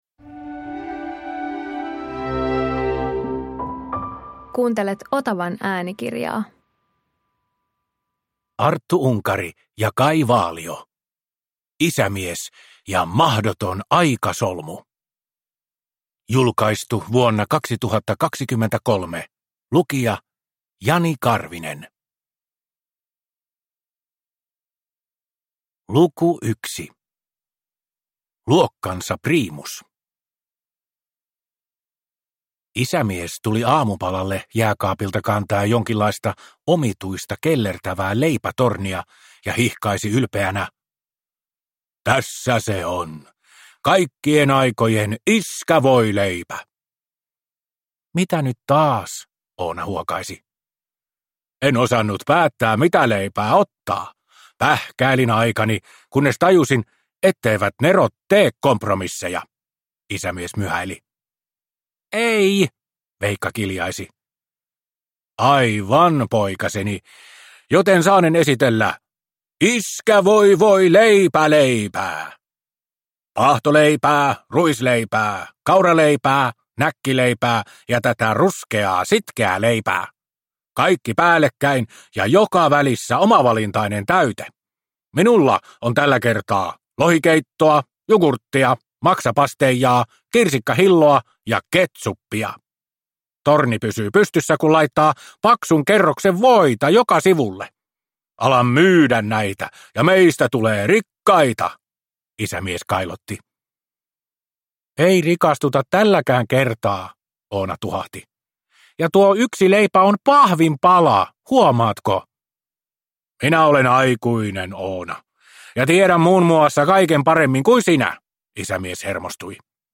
Isämies ja mahdoton aikasolmu – Ljudbok – Laddas ner